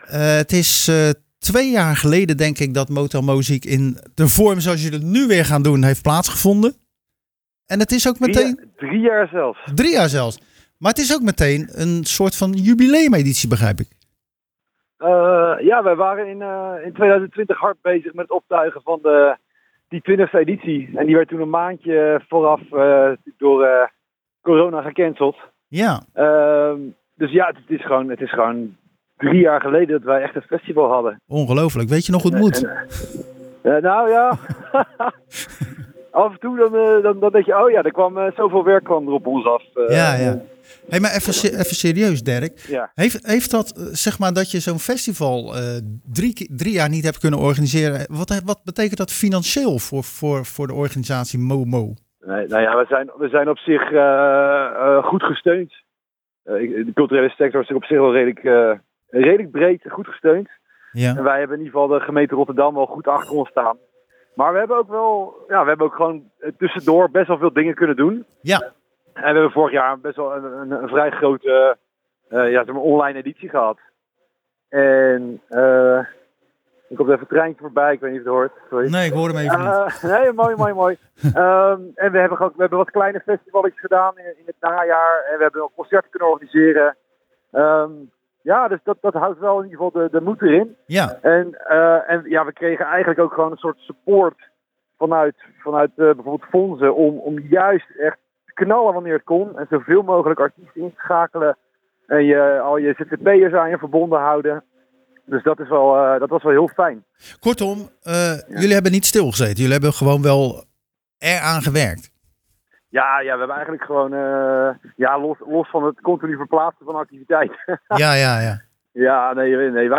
Tijdens de wekelijkse editie van Zwaardvis belde we met